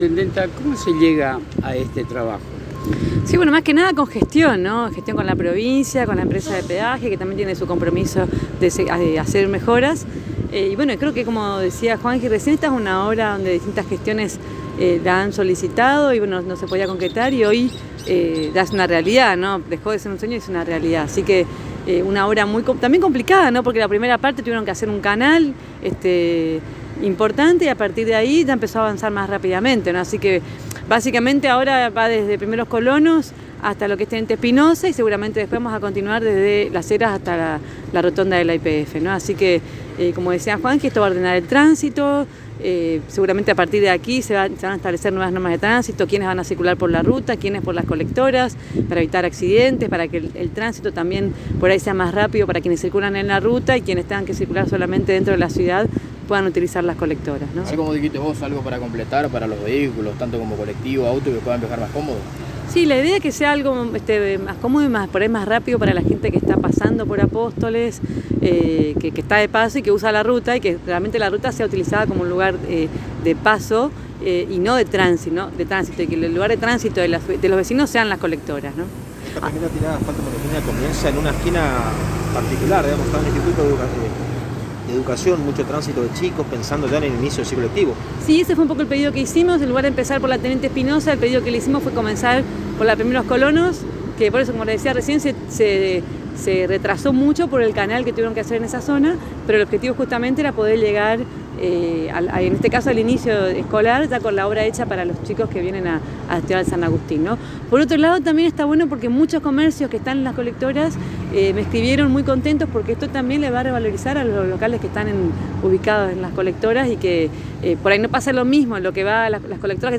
Desde los cronistas de la ANG se le consultó a María Eugenia Safrán sobre cómo se logró realizar esta obra la mandataria municipal afirmó que es gracias a las gestiones realizadas desde el Municipio y la buena predisposición del Gobierno Provincial y de la Concesionaria de la Ruta 105.